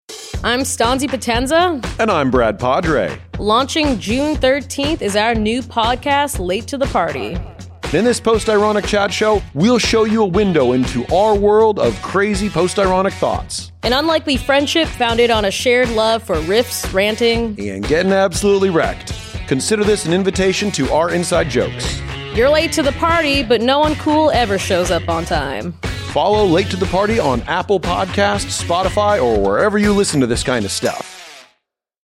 Facebook Twitter Headliner Embed Embed Code See more options In this post-ironic weekly chat show featuring two of the most outrageous and hilarious content creators on the internet